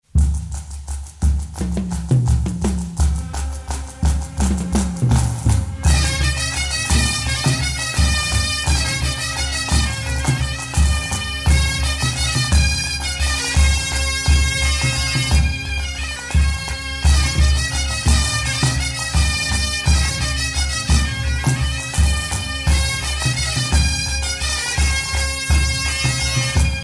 Tons simples et bals à huit